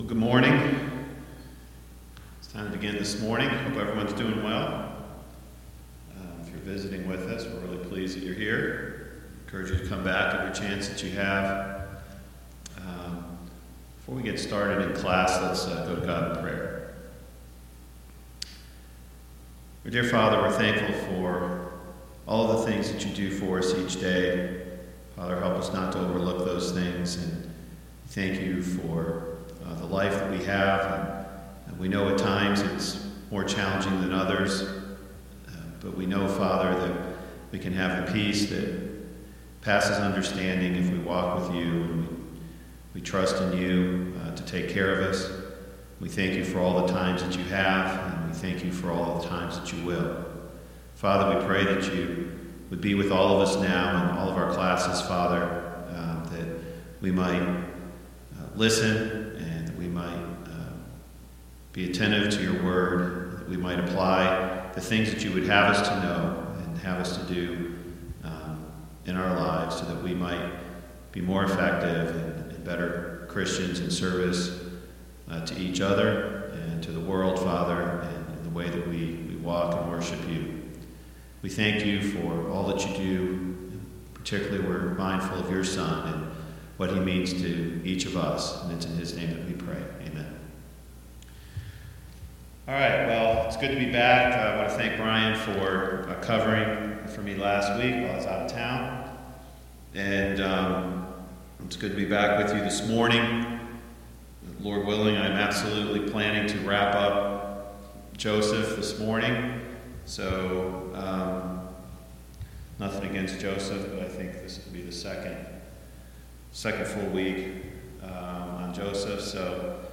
Service Type: Sunday Morning Bible Class Topics: Fear , Hatred , Jealousy